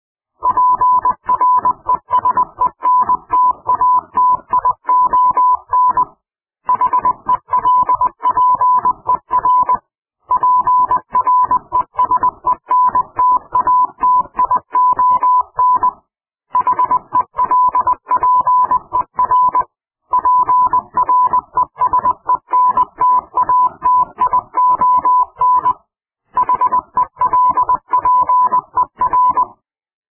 Morse code
Here is a blast of Morse code available as a ring tone.
morse_long.mp3